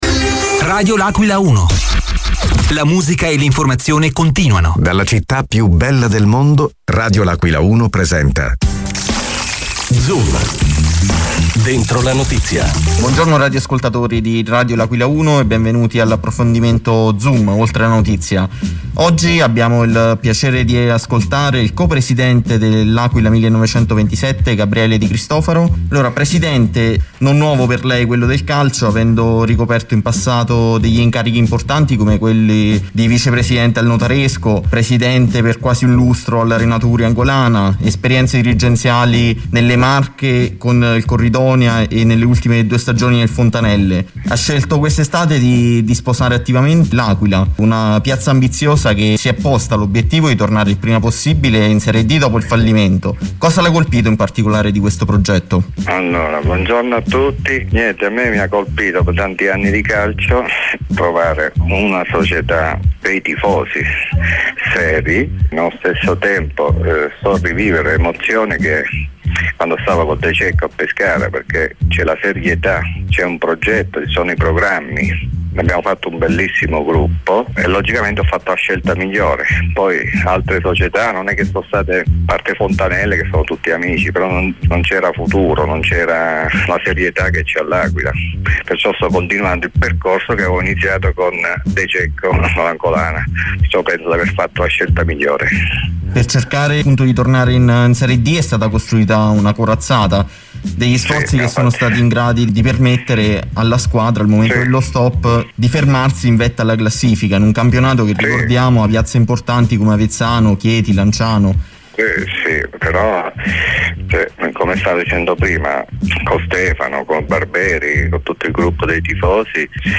Zoom, dentro la notizia del 16.12.2020: Intervista